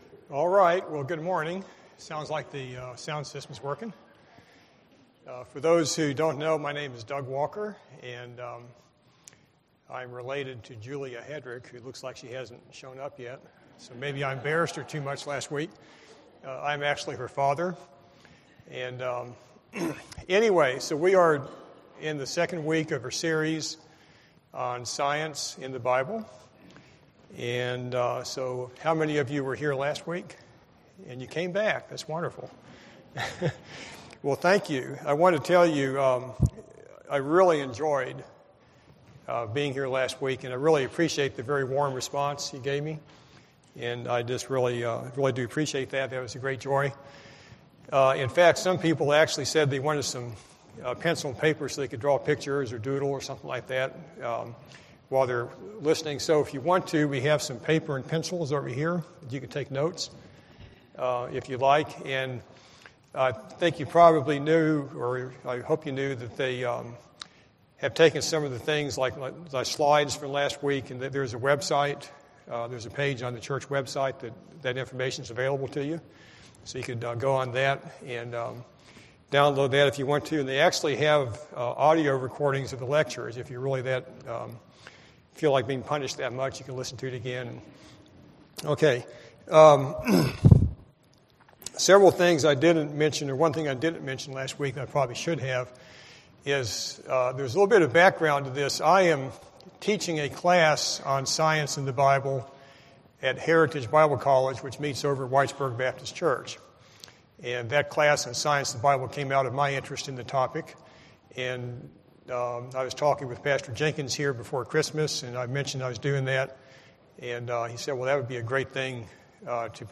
Sermons Online